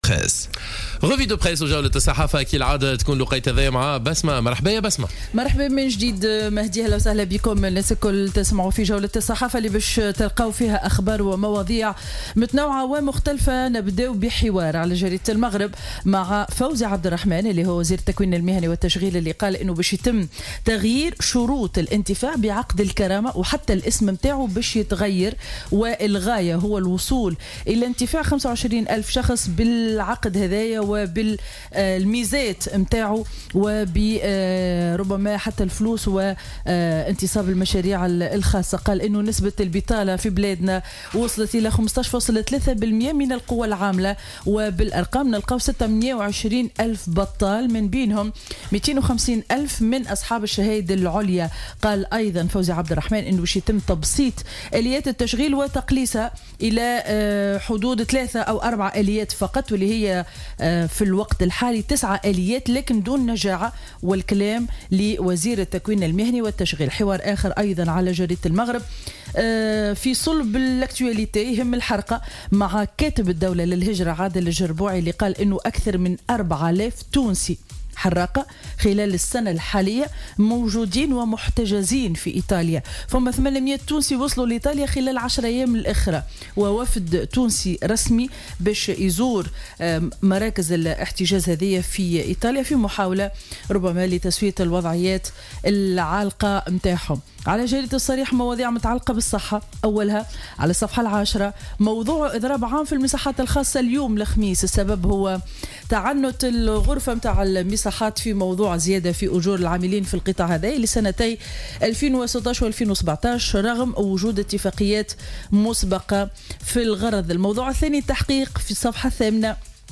معرض الصحافة ليوم الخميس 19 أكتوبر 2017